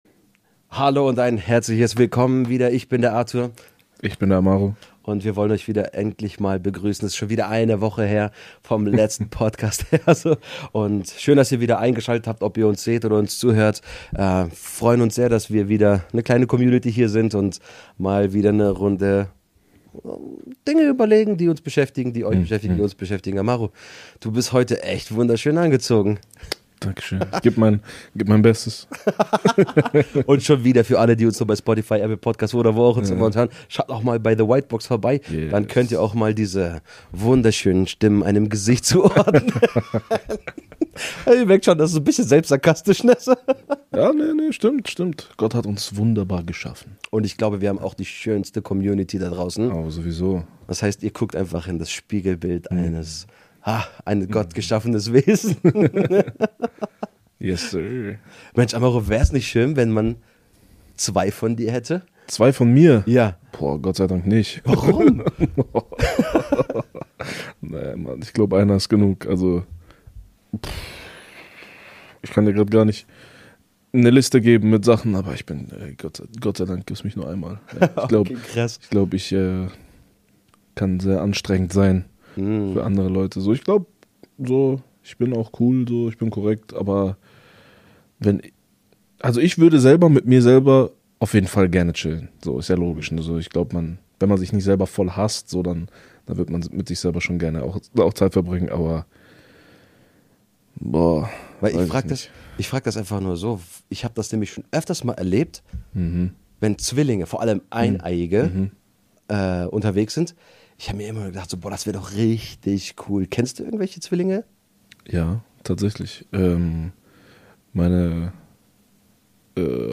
Zwei Kumpels, ehrliche Gespräche, echte Fragen – und eine Menge Inspiration fürs Leben.